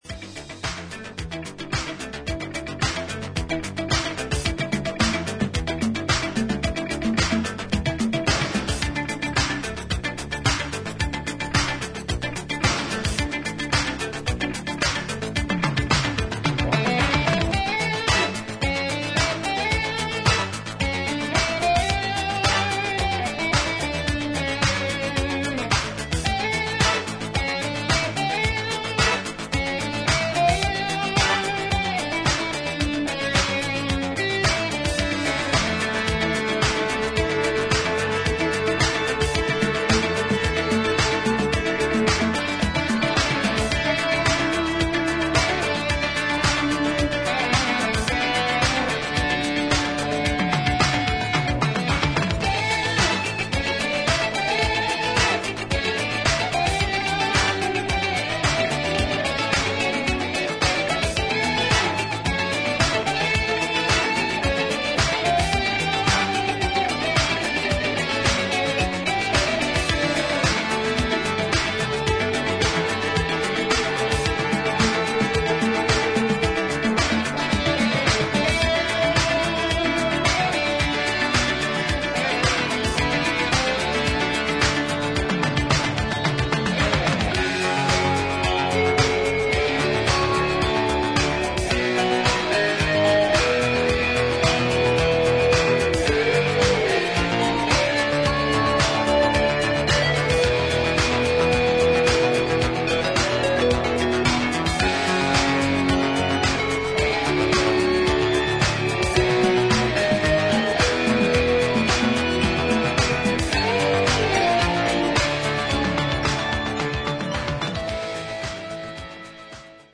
エレクトロニックでパーカッシブなスペイシー・フュージョン/ディスコ・ミックスが2ヴァージョン収録。